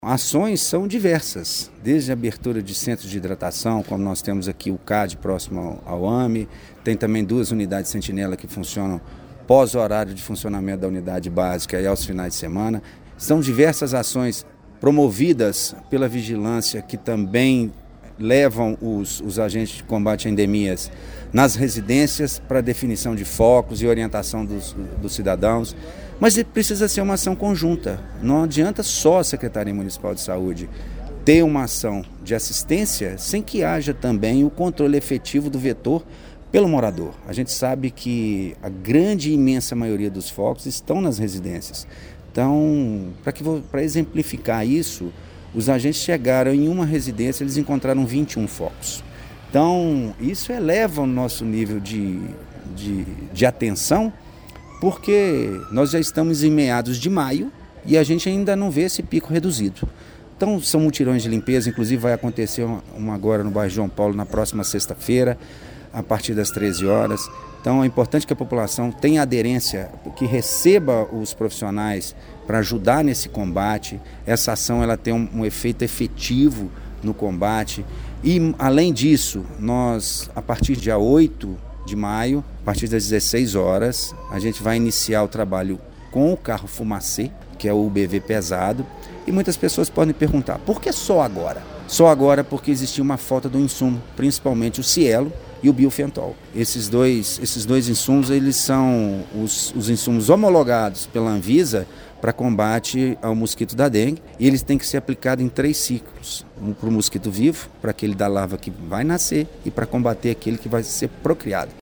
O secretário municipal de Saúde, Wagner Magesty, destaca ações realizadas para conter a proliferação do mosquito Aedes aegypti, em meio a maior epidemia de Dengue já registrada no hemisfério sul, desde a ação dos agentes em campo, passando pelo tratamento e constatação que os casos ainda não apresentam tendência de queda.
Wagner Magesty confirma que a partir desta quarta-feira, 8 de maio, será utilizado o fumacê em mais uma frente de combate ao mosquito e diz porque demorou para iniciar a aplicação do inseticida no município: